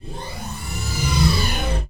hangar.wav